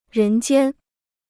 人间 (rén jiān) พจนานุกรมจีนฟรี